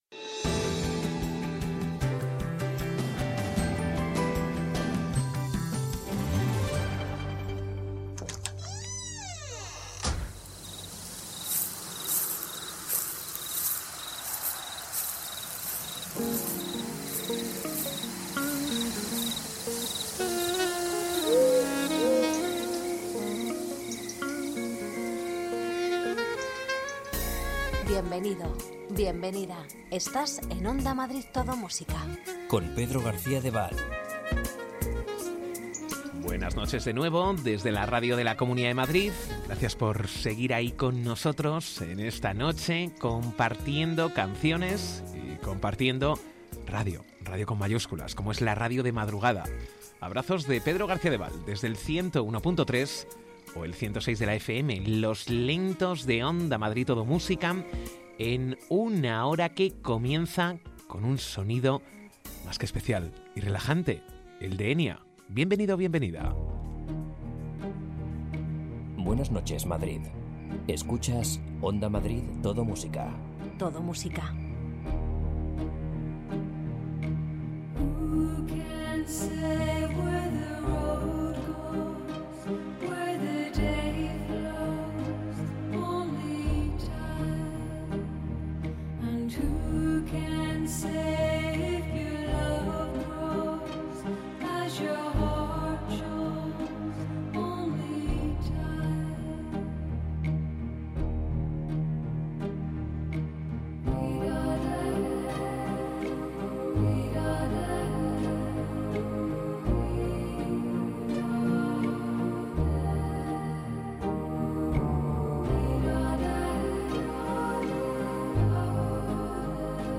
Los mejores lentos
Ritmo tranquilo, sosegado, sin prisas...